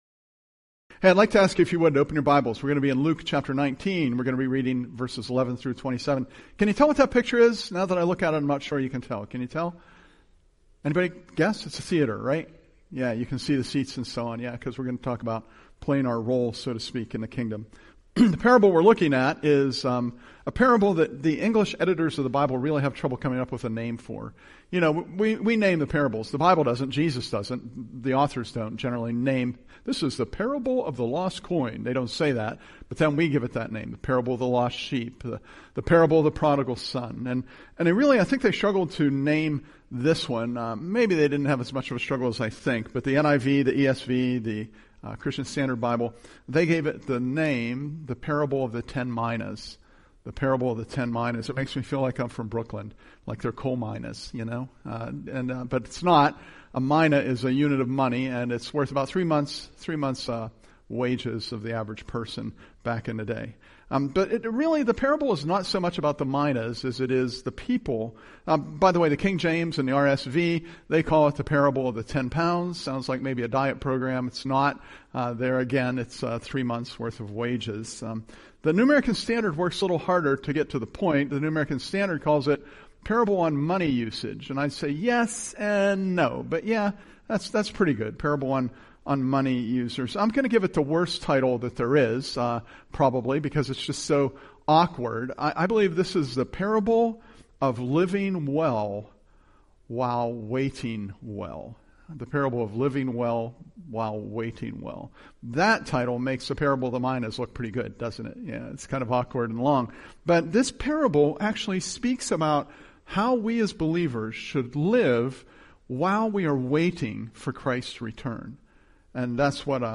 Presented at Curwensville Alliance